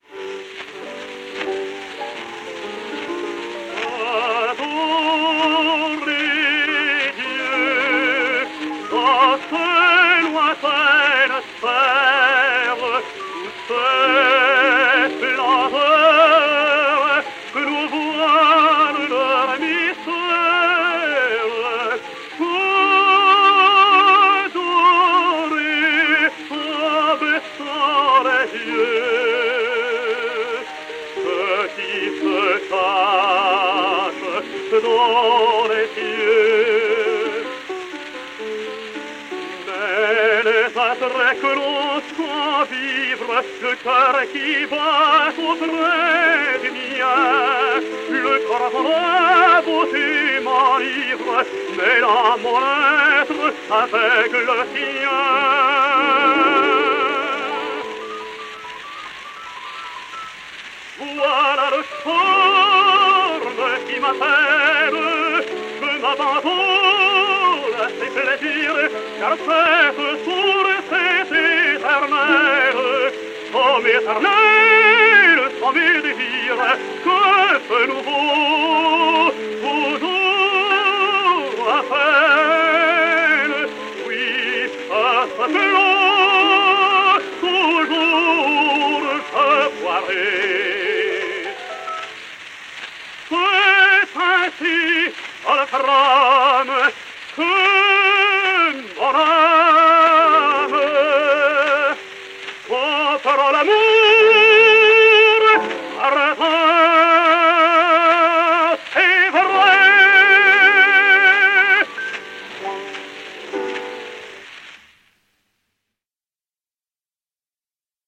• Charles Rousselière sings Tannhäuser: